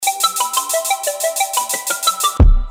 • Качество: 320, Stereo
Electronica
звонкие
Коротенькая звонкая мелодия на сообщение